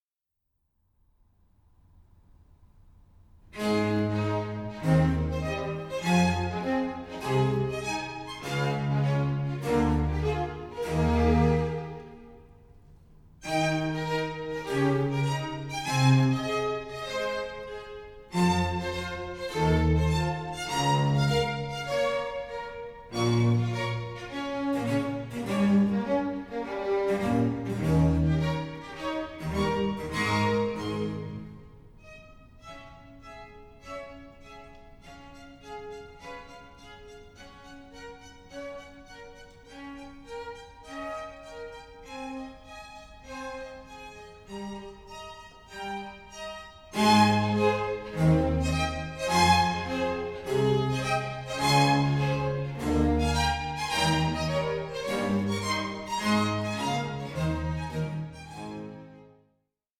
Organ Concerto No.14 in A major